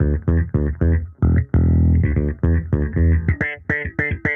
Index of /musicradar/sampled-funk-soul-samples/110bpm/Bass
SSF_JBassProc1_110E.wav